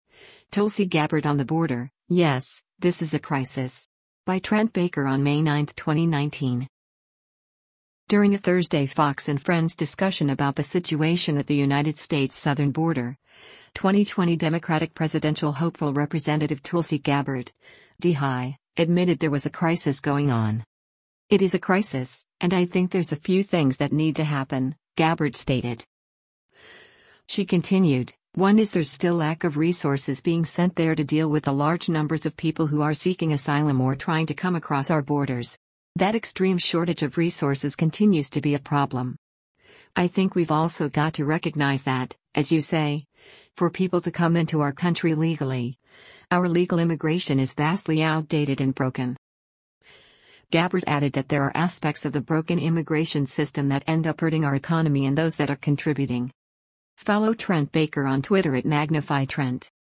During a Thursday “Fox & Friends” discussion about the situation at the United States’ southern border, 2020 Democratic presidential hopeful Rep. Tulsi Gabbard (D-HI) admitted there was a “crisis” going on.